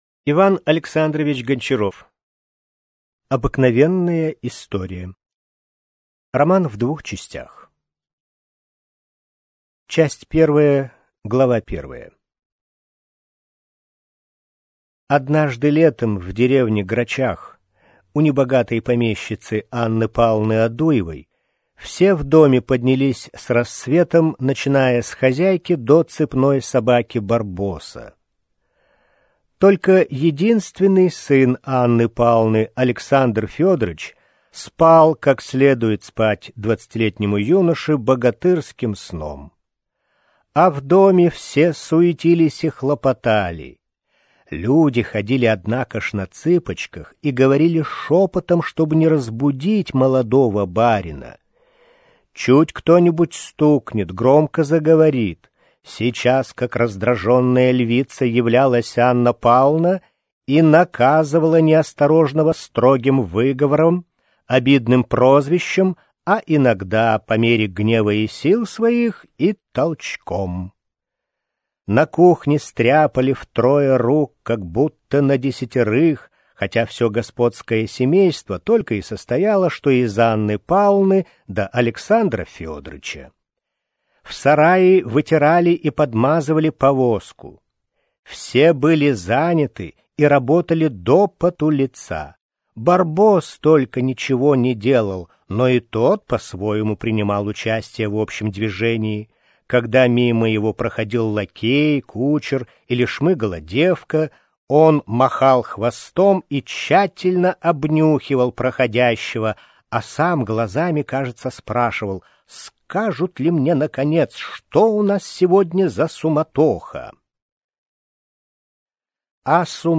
Аудиокнига Обыкновенная история - купить, скачать и слушать онлайн | КнигоПоиск